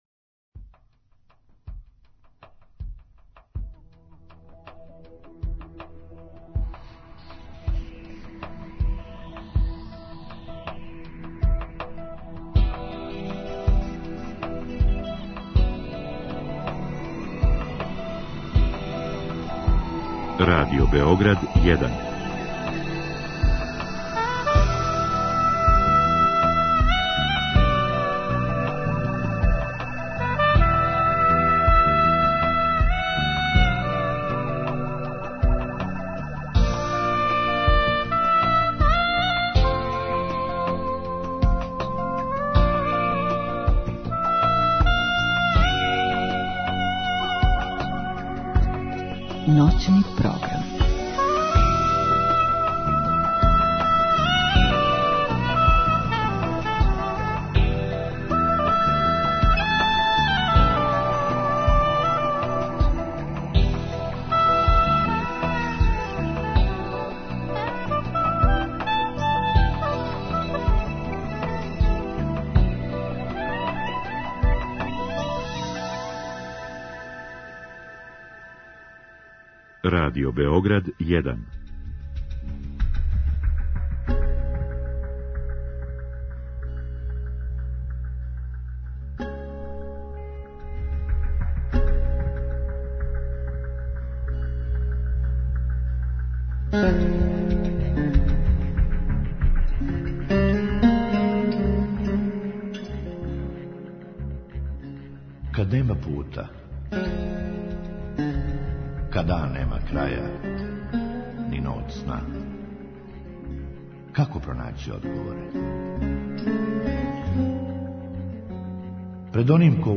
У другом сату телефонске линије Радио Београда 1 биће отворене за слушаоце, који ће у директном програму моћи да поставе питање госту.